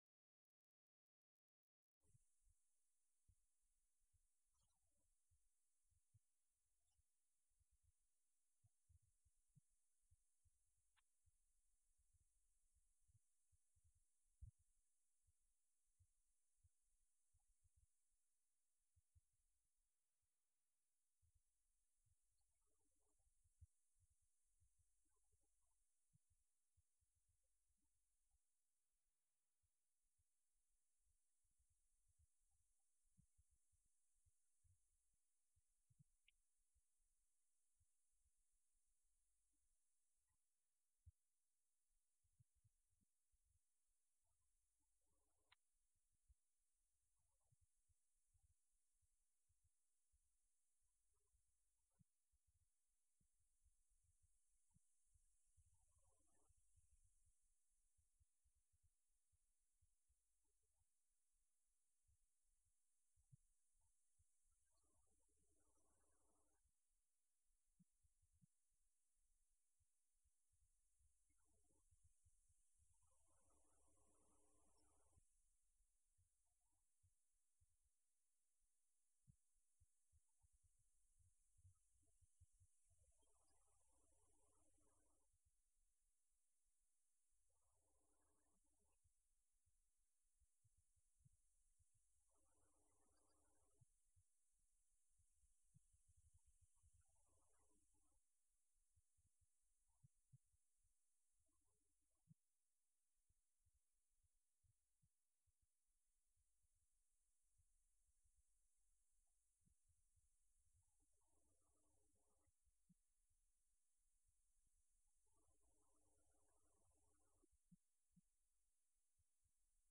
Event: 5th Annual Arise Workshop
lecture